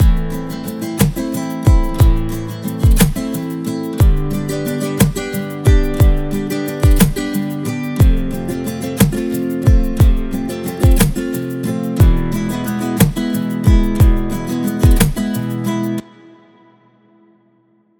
لوپ 6/8 اسلو گیتار نایلون شماره 2 (پلاس) | هنر صدا
لوپ 6/8 اسلو گیتار نایلون شماره 2 48 آکورد مینور , ماژور و سون به صورت Left و Right
preview-slow-vol2-nylon.mp3